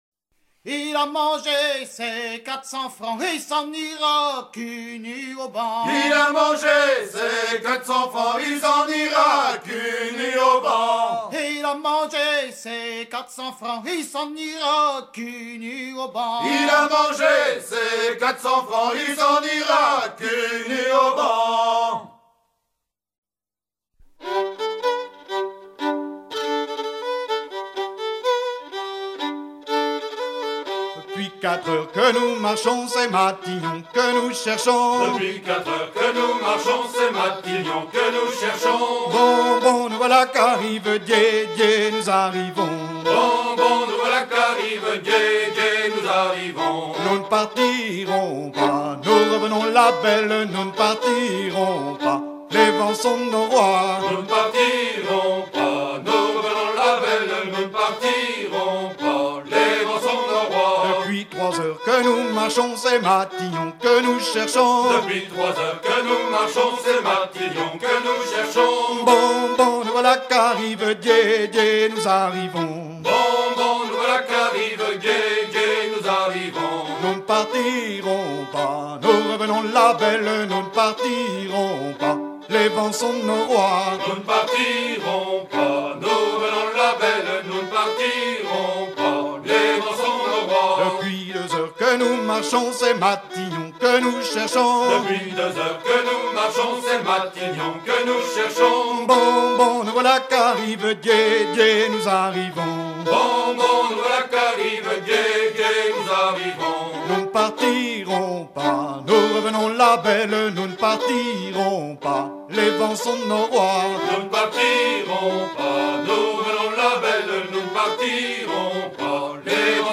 recueilli à la Ville-es-Nonais, sur les bord de Rance
Fonction d'après l'analyste gestuel : à marcher
Genre énumérative
Pièce musicale éditée